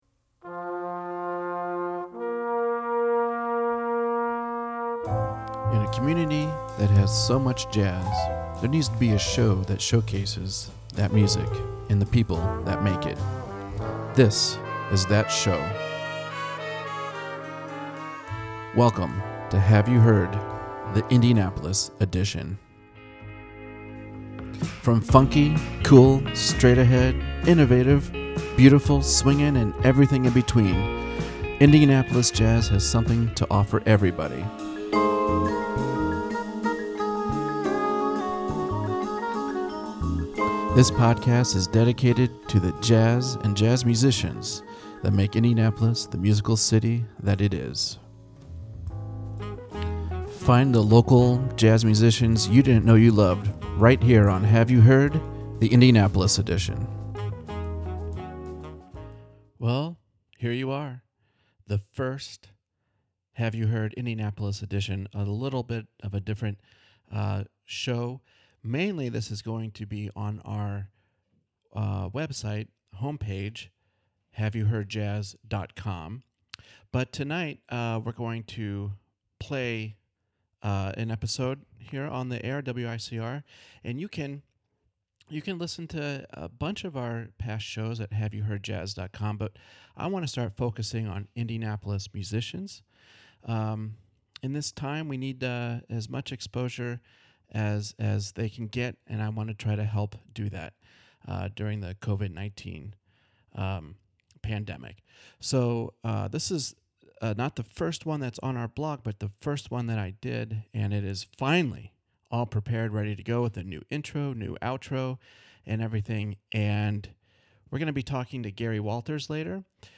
Once I got the technology nailed down to record audio from internet calls (it doesn’t take much to confuse me), the conversation was great!